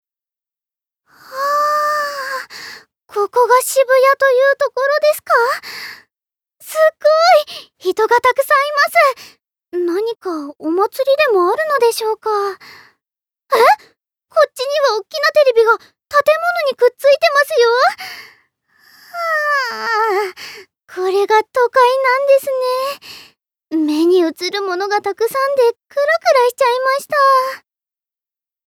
ボイスサンプル
セリフ１